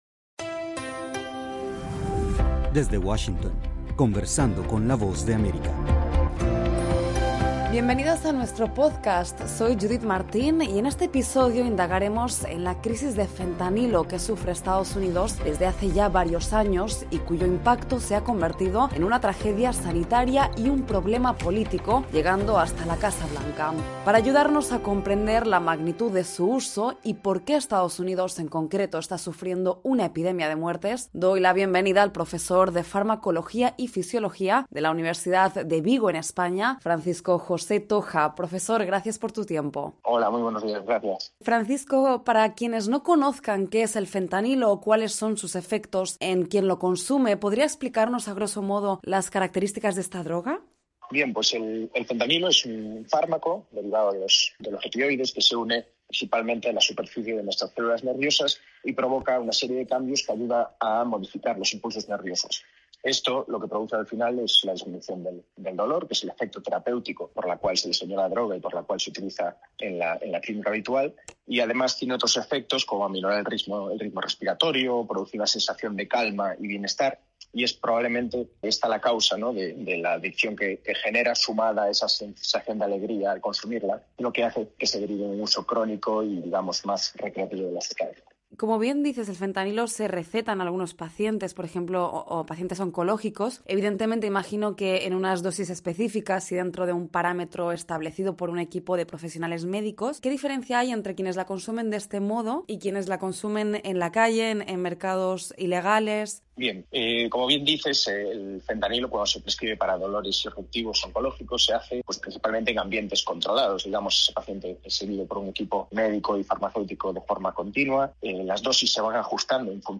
conversamos con el profesor